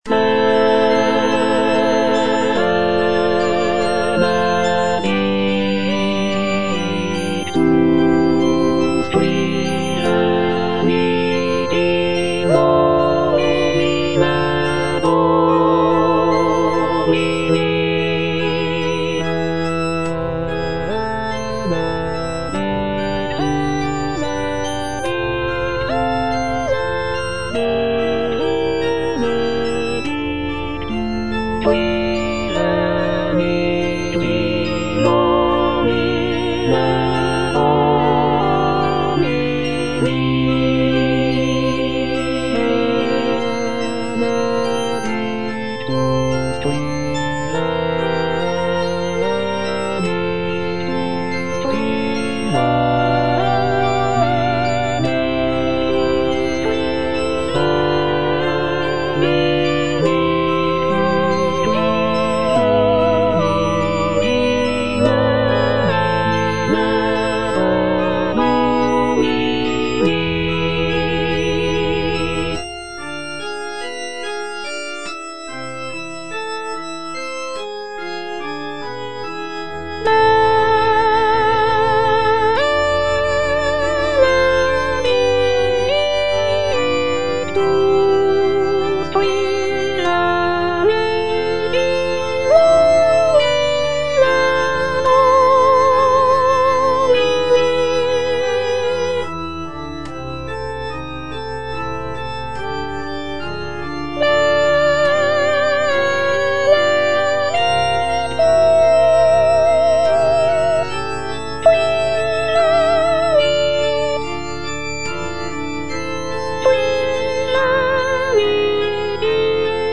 J.G. RHEINBERGER - MISSA MISERICORDIAS DOMINI OP.192 Benedictus - Soprano (Voice with metronome) Ads stop: auto-stop